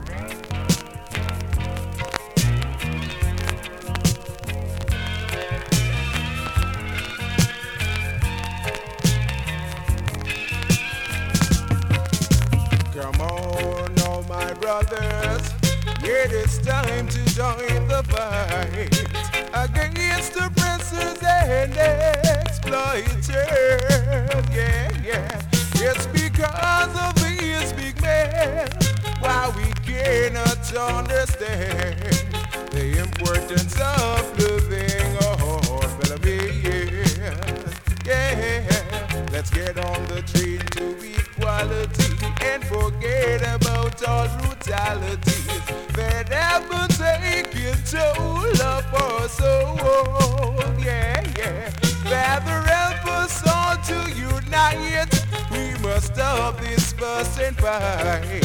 NEW IN!SKA〜REGGAE
スリキズ、ノイズそこそこあります。